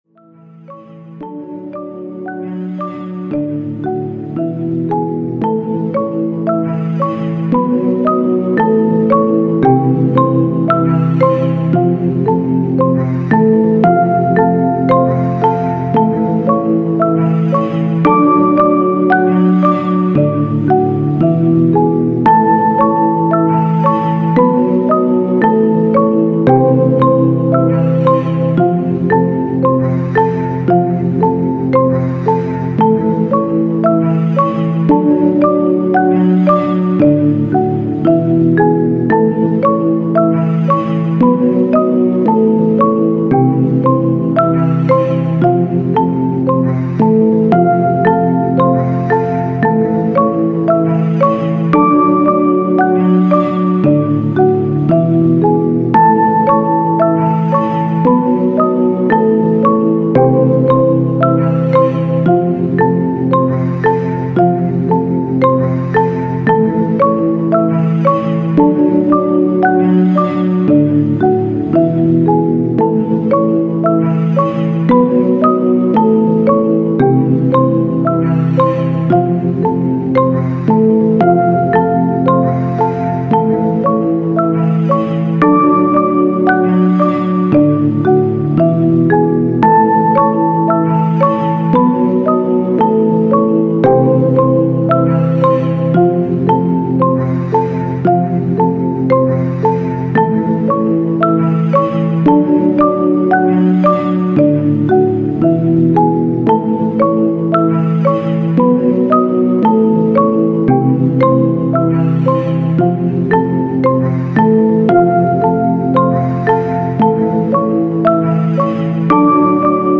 • Category: Sounds for babies